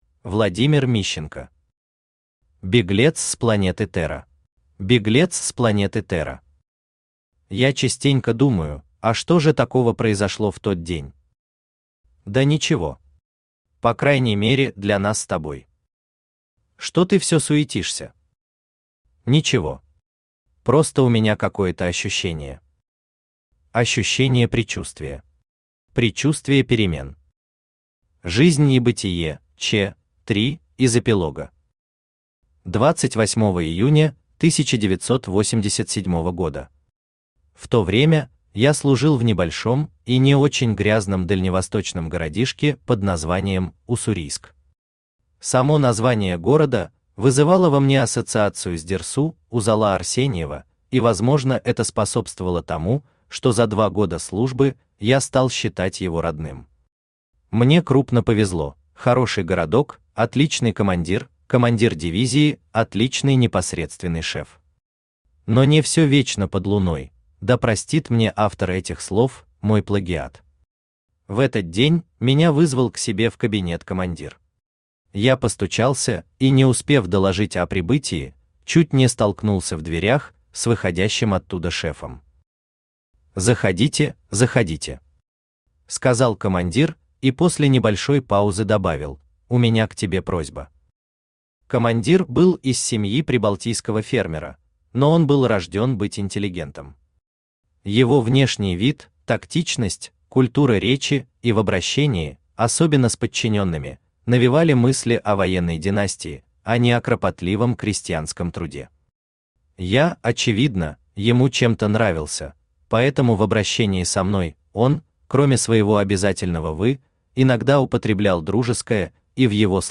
Aудиокнига Беглец с планеты Терра Автор Владимир Мищенко Читает аудиокнигу Авточтец ЛитРес.